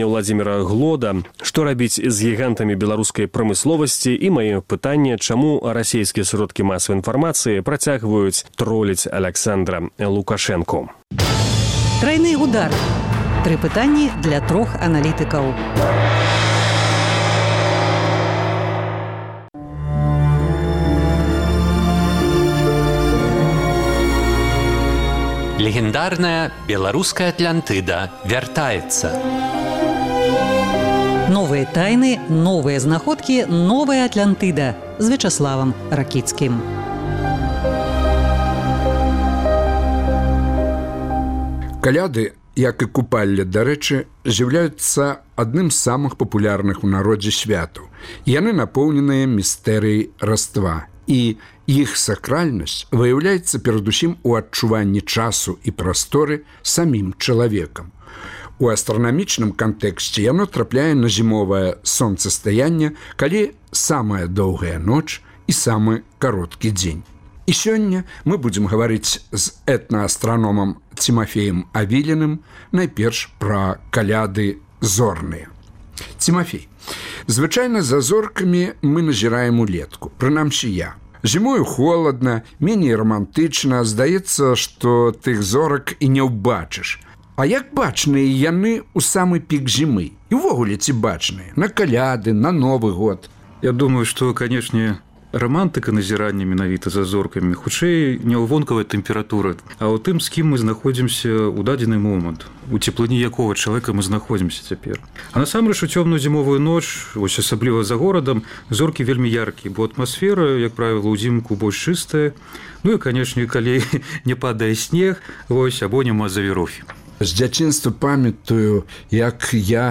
Пра Каляды зорныя гутарка